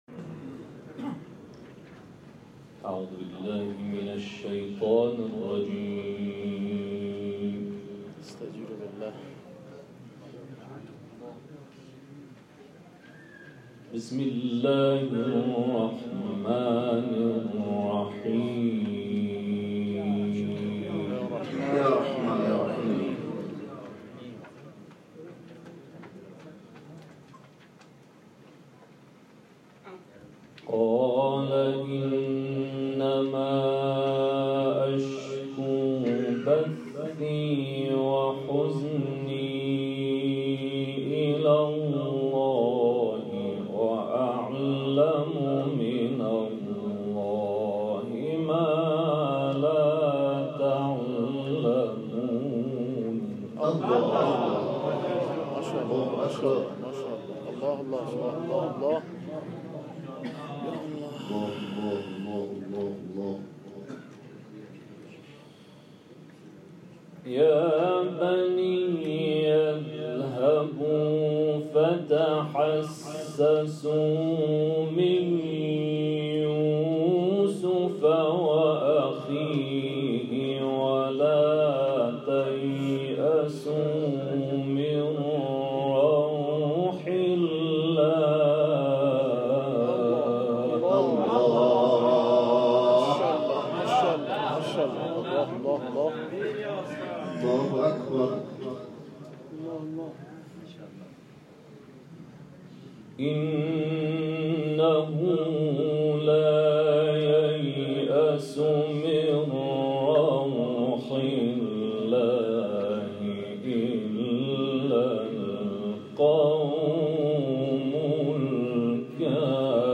در جلسه محبان عترت مشهد
تلاوت آیاتی از سوره مبارکه یوسف